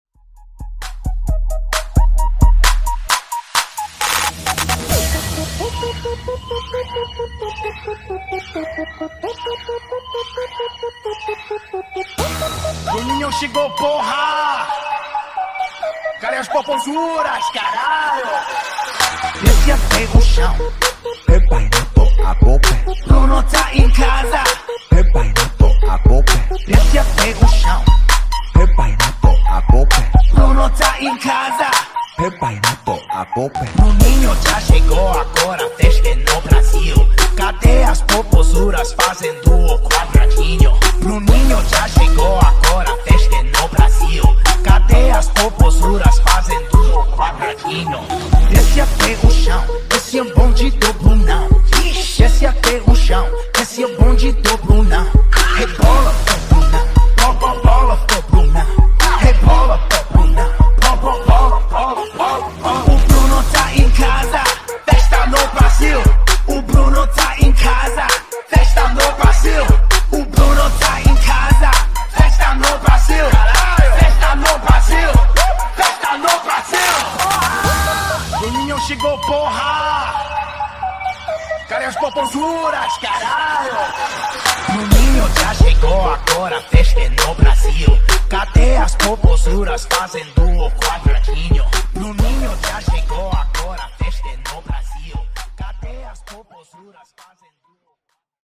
Genre: RE-DRUM Version: Dirty BPM: 113 Time